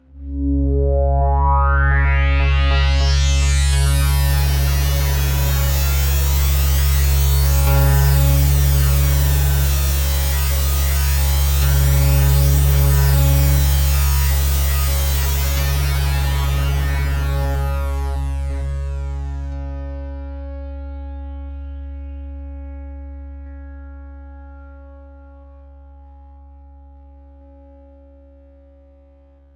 标签： CSharp4 MIDI音符-61 Korg的-Z1 合成器 单票据 多重采样
声道立体声